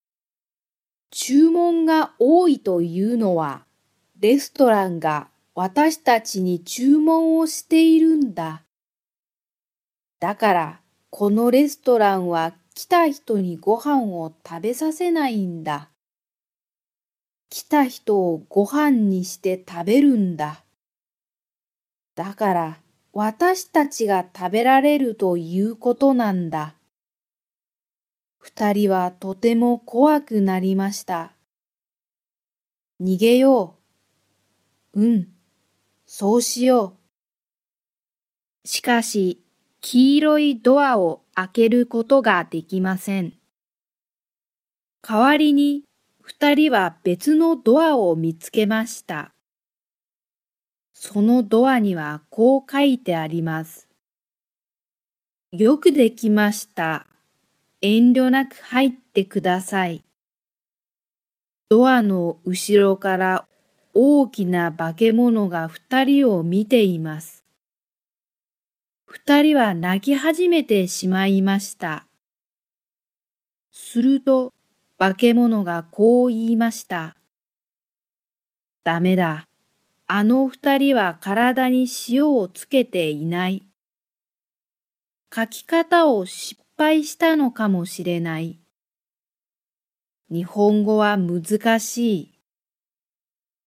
Japanese Graded Readers: Fairy Tales and Short Stories with Read-aloud Method
Slow Speed